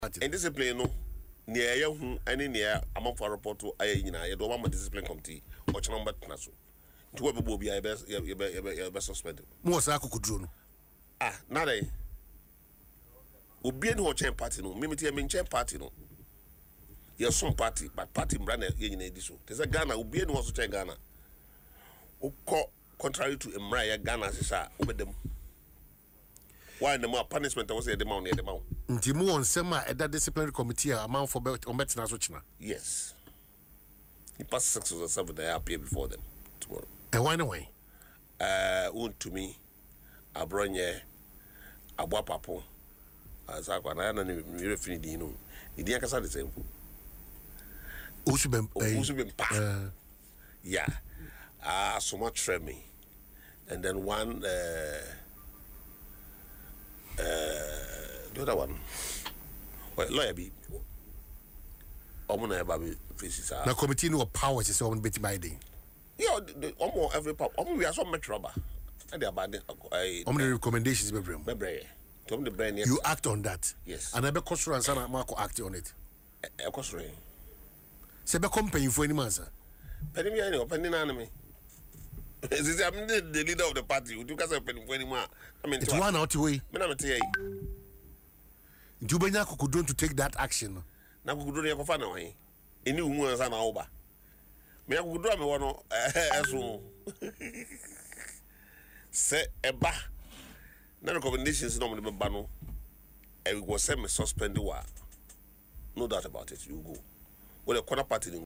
Mr. Butey, who also serves as the first National Vice-Chairman, made this known in an interview on Adom FM’s Dwaso Nsem show.